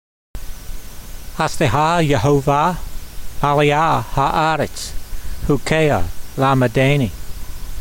Sound (Psalm 119:64) Transliteration: hasde ha ye hovah ma'le' ah ha' ar ets hu key ha lame day nee Vocabulary Guide: Your mercy , Lord , fills the earth , teach me your statutes . Translation: Your mercy, Lord, fills the earth, teach me your statutes.